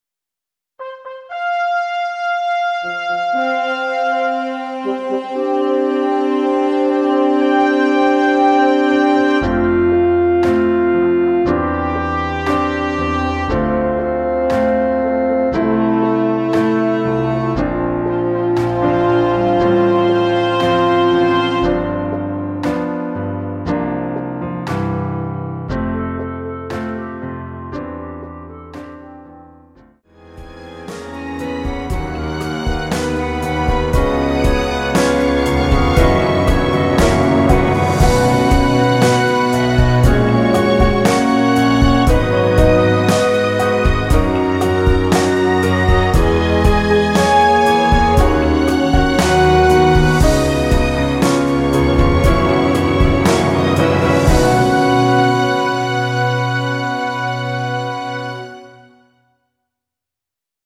엔딩이 너무 길어 라이브에 사용하시기 좋게 짧게 편곡 하였습니다.(원키 미리듣기 참조)
원키 멜로디 포함된 MR입니다.
앞부분30초, 뒷부분30초씩 편집해서 올려 드리고 있습니다.